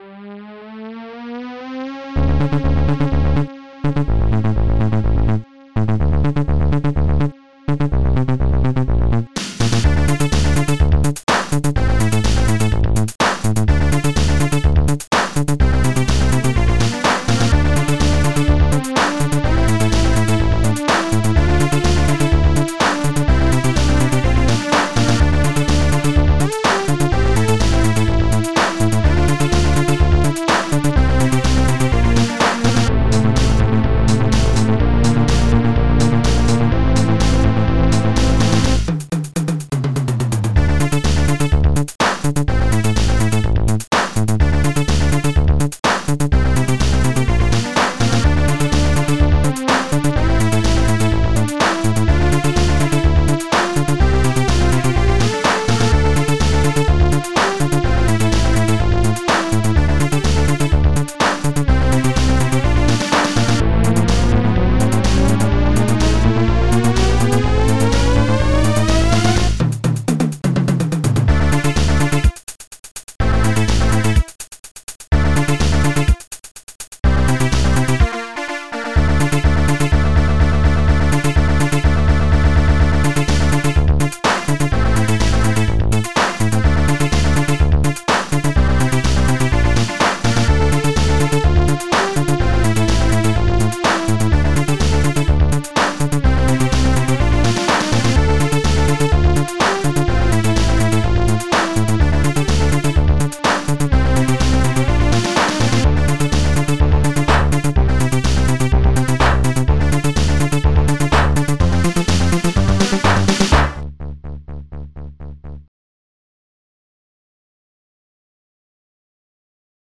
Durbe dip durbe dip !!!! Tracker Archimedes Tracker Tracks 4 Samples 36 Patterns 16 Instruments beat basis Cymbals Quiet Thump High cymbals Drum bang Synthesise Synth loop Hollow drum